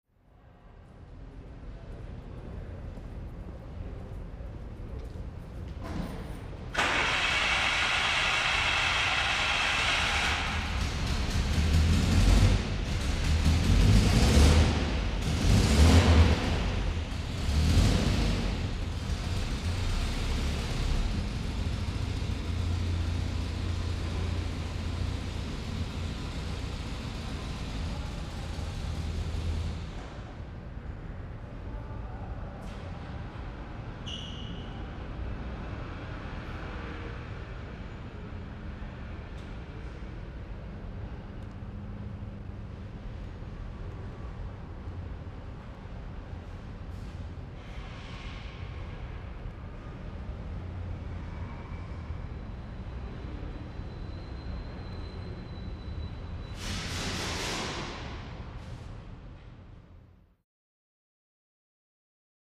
Auto Shop
Automobile Body Shop Ambience, W Pneumatic Tools, Various Activity, Car Start Idle Off.